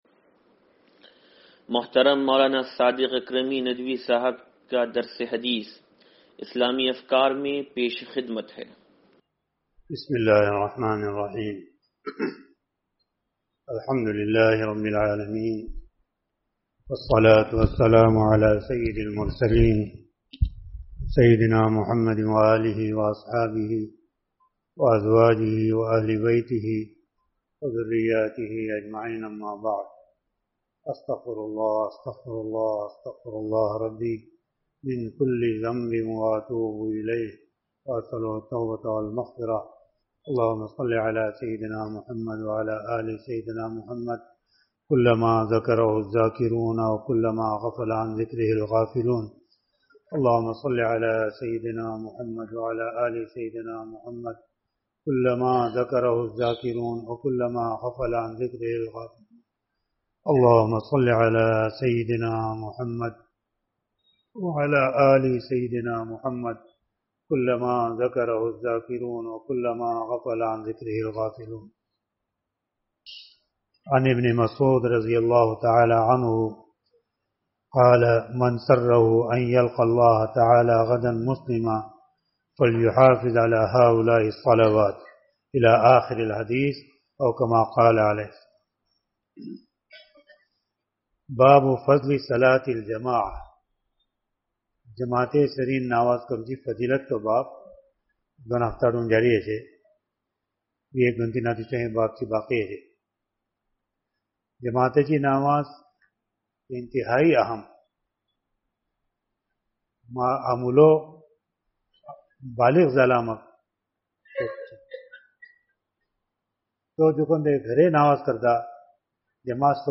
درس حدیث نمبر 0815